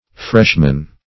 Freshman \Fresh"man\, n.; pl. Freshmen.